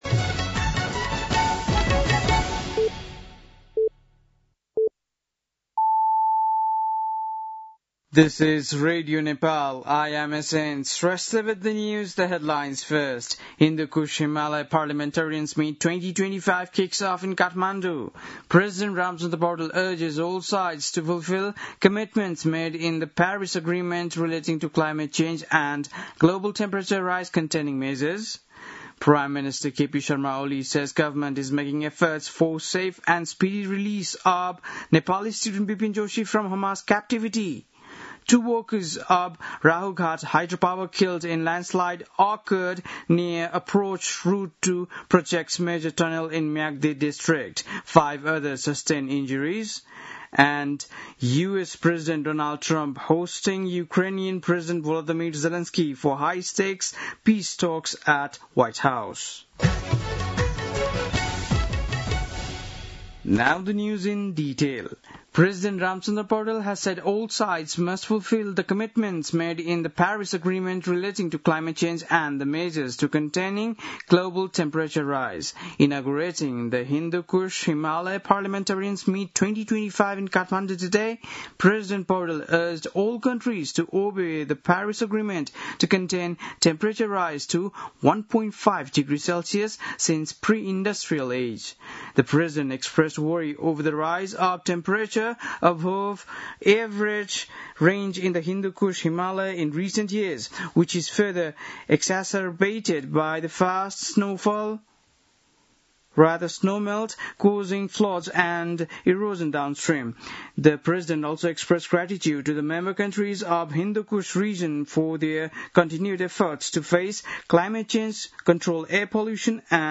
बेलुकी ८ बजेको अङ्ग्रेजी समाचार : २ भदौ , २०८२
8-pm-english-news-5-02.mp3